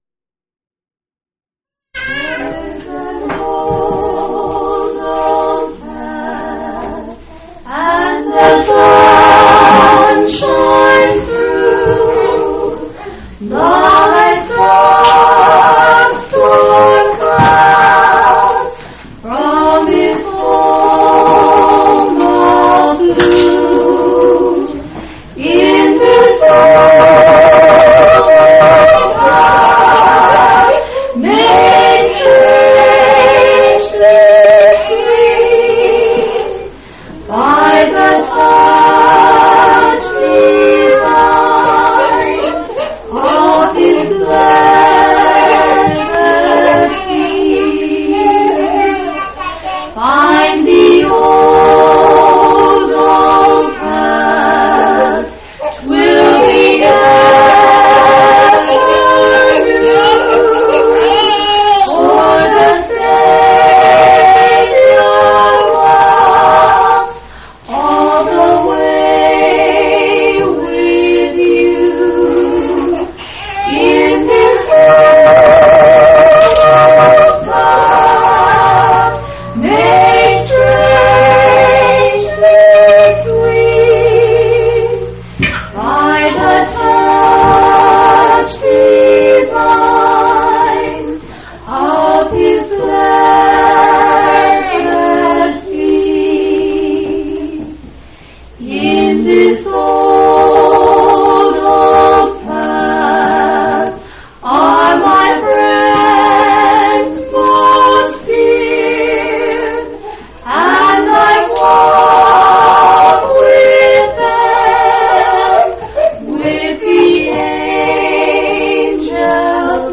6/10/1989 Location: Colorado Reunion Event